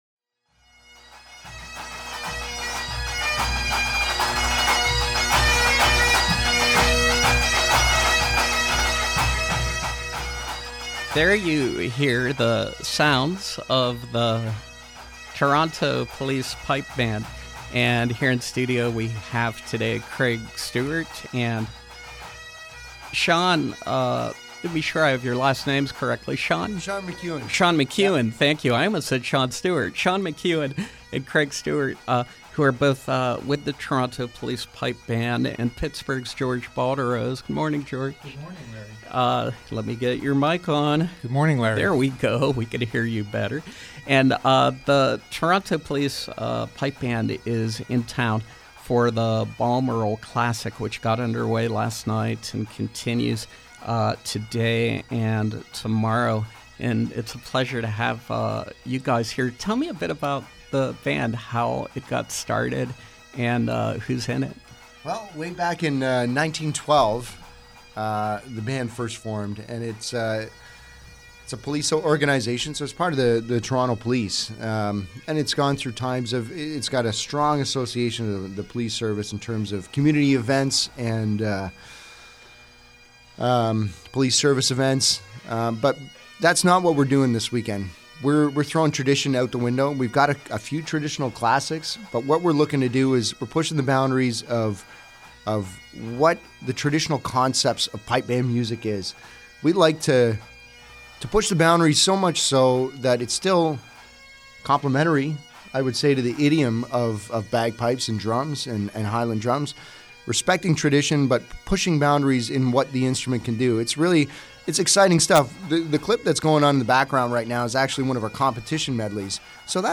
Interview: Balmoral Classic